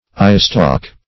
Search Result for " eyestalk" : The Collaborative International Dictionary of English v.0.48: Eyestalk \Eye"stalk`\, n. (Zo["o]l.)